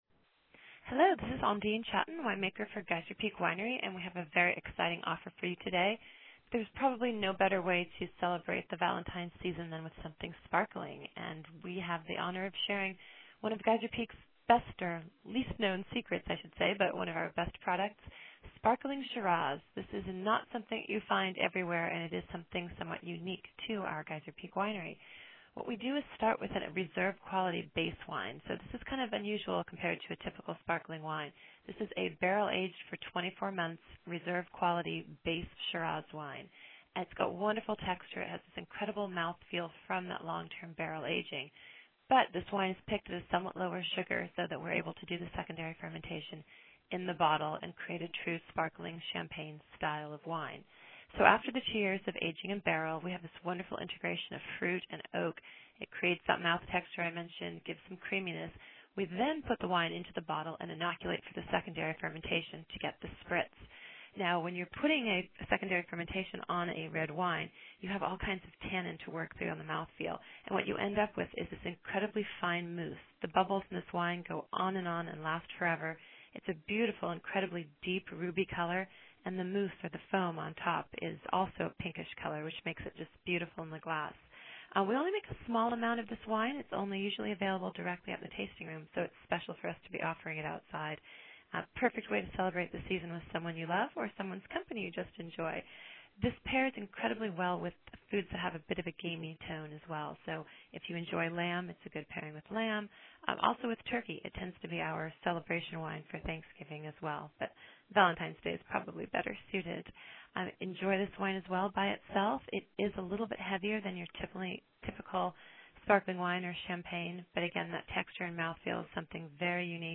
Vintner Voicemail